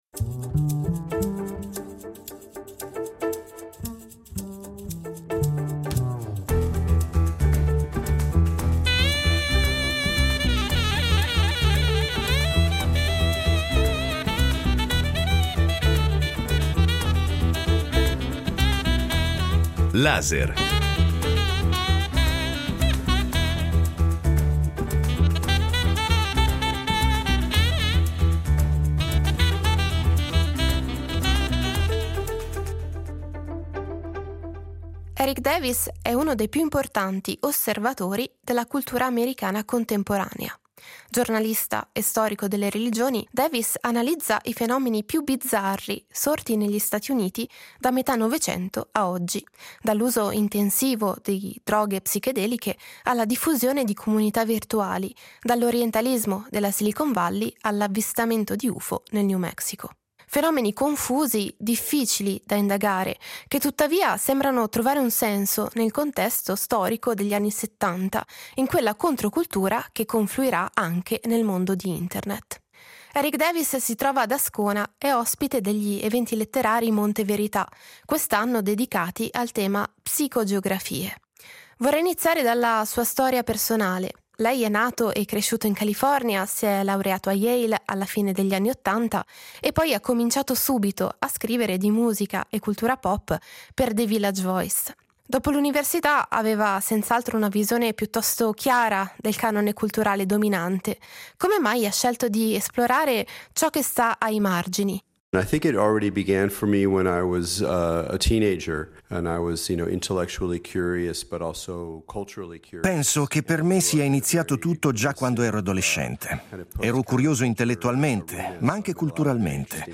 In un mondo in cui internet ci permette di fare praticamente qualsiasi cosa e l’intelligenza artificiale neuronale è ormai un dato di fatto, Davis cerca di rispondere a una domanda: cosa vuol dire essere umani oggi? Ne parliamo con Erik Davis ad Ascona, dove è ospite degli Eventi letterari Monte Verità , quest’anno a tema “Psicogeografie” in occasione del 150° anniversario della nascita di Carl Gustav Jung.